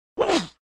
target_impact_grunt1.ogg